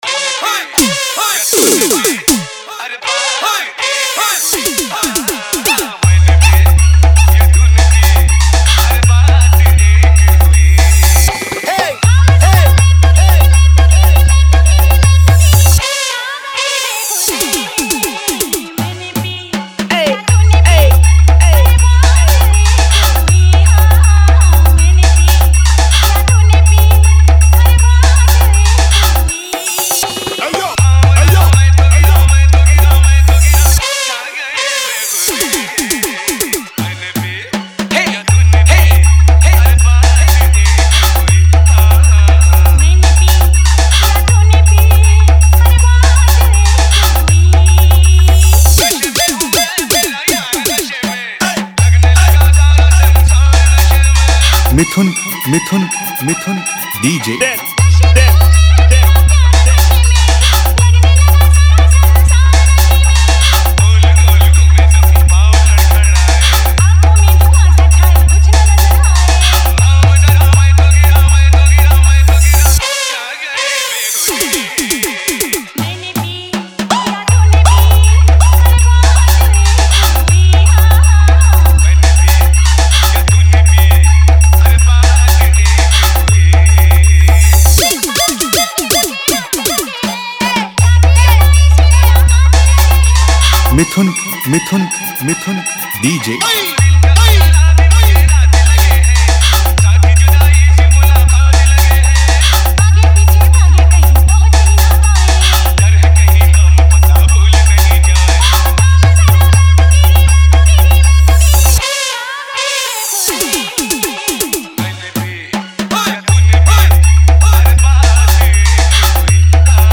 Hindi New Style Dance Dhamaka Mix